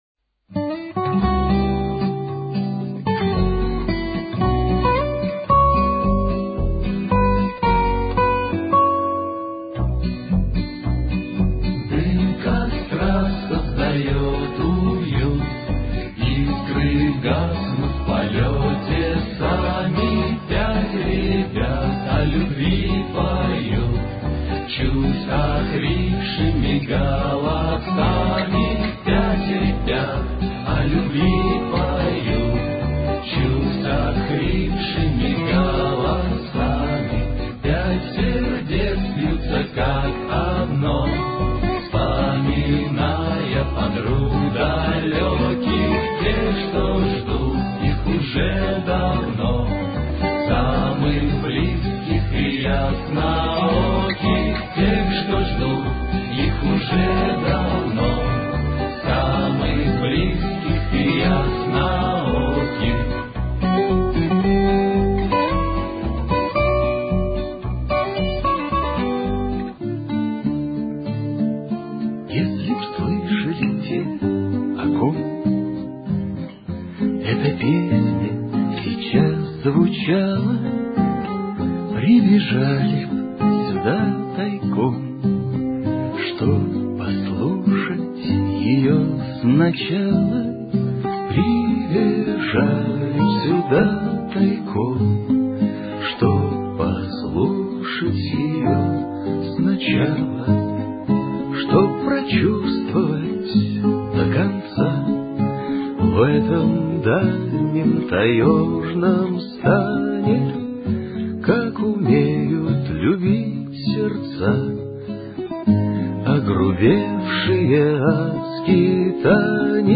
СЛУШАТЬ  попурри